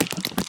mob / spider / step3.ogg
step3.ogg